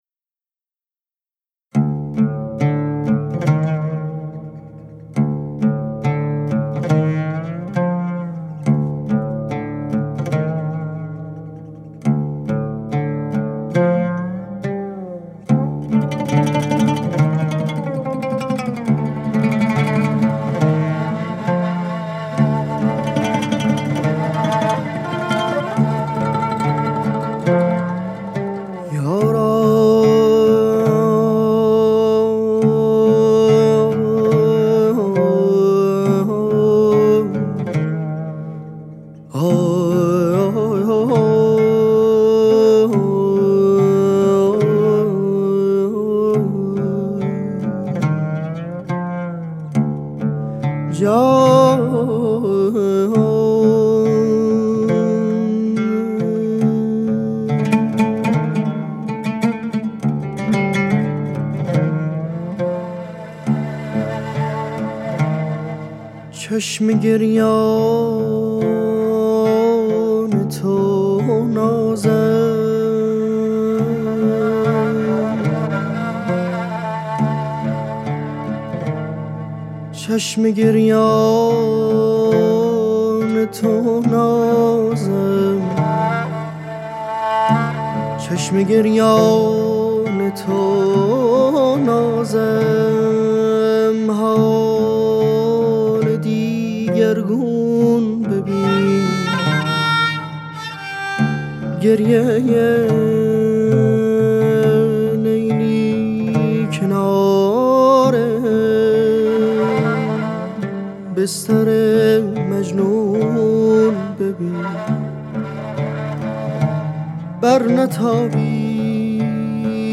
کمانچه
عود